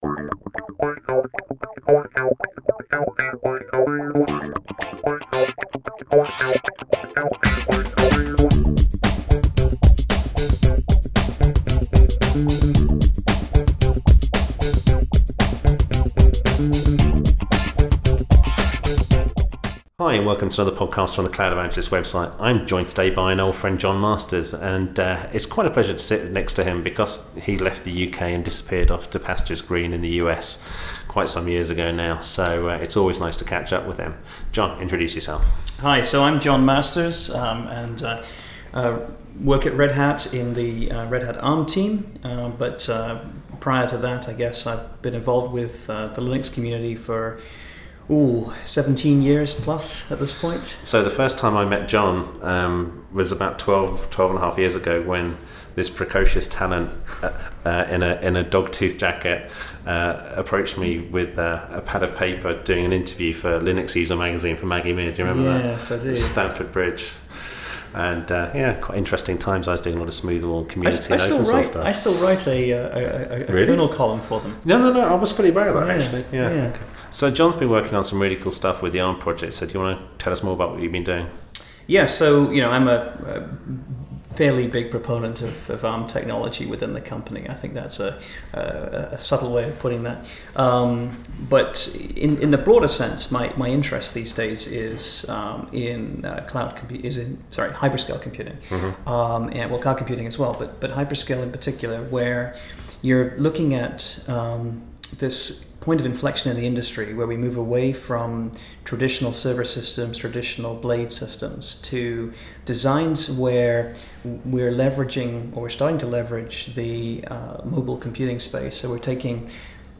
Having spent brief time together in Boston recently he'd promised to record something so we rented some office space near his folks for a few hours and this is the result.
It's two geeks having a chat and talking technology as well as the goodness of emerging tech in the Cloud space.